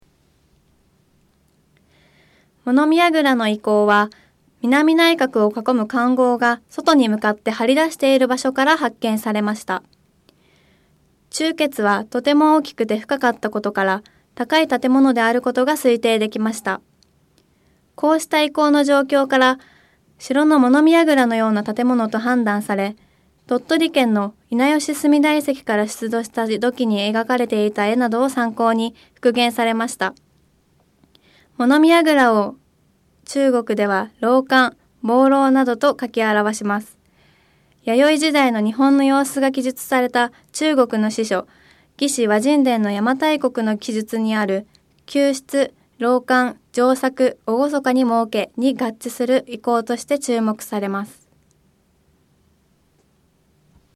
音声ガイド 前のページ 次のページ ケータイガイドトップへ (C)YOSHINOGARIHISTORICAL PARK